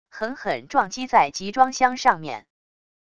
狠狠撞击在集装箱上面wav音频